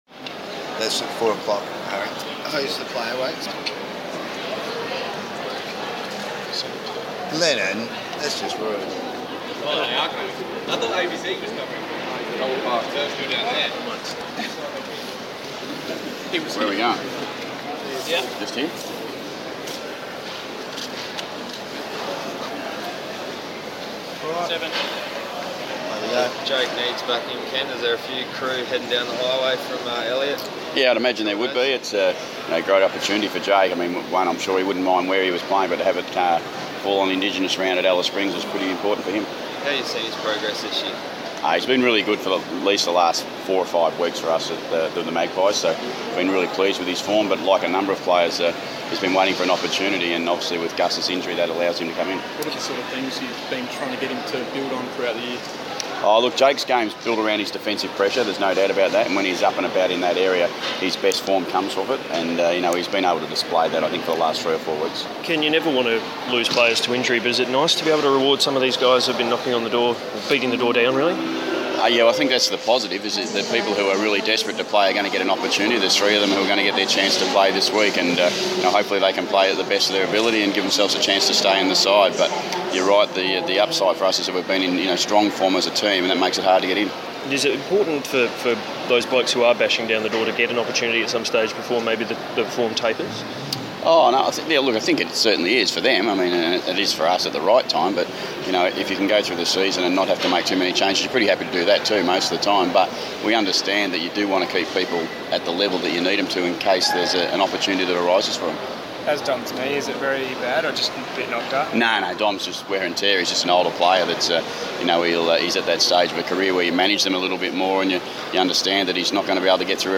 Ken Hinkley press conference - Friday 30 May, 2014